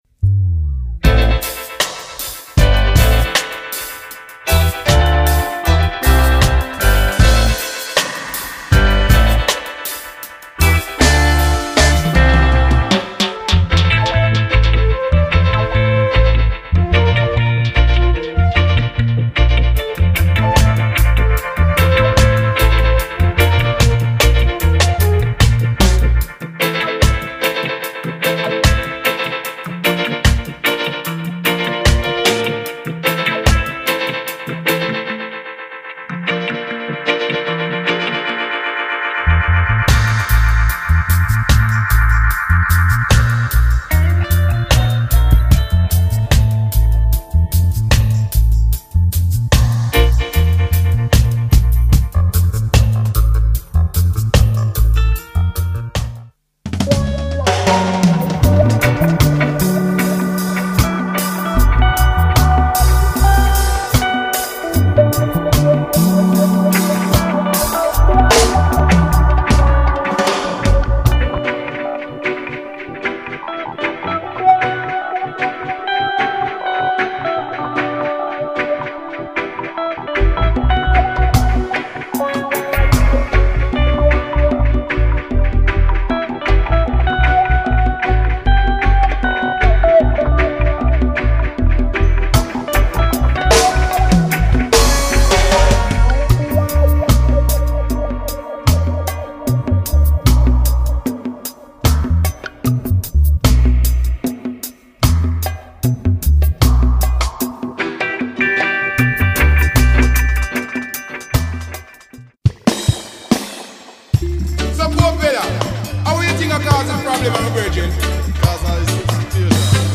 the backing band of reggae singer Gentleman.